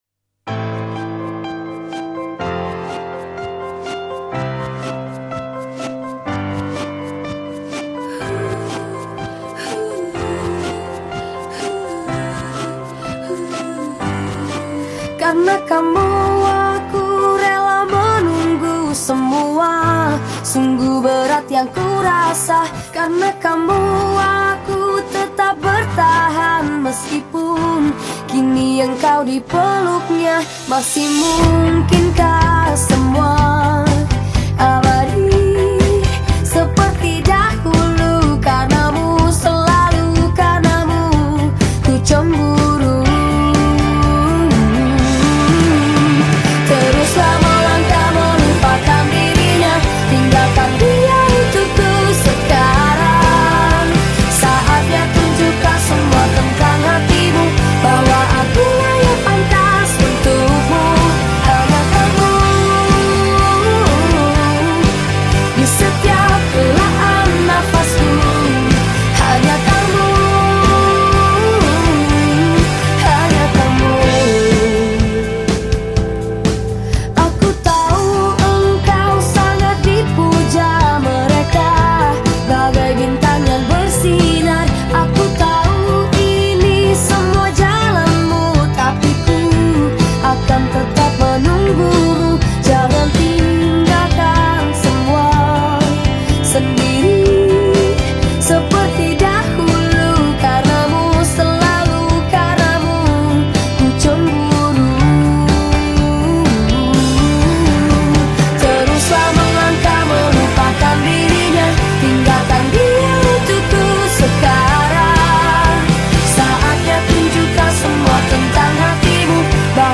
Dengan mengusung aliran art rock progressive
yakni sentuhan rock dan alunan beat yang kencang.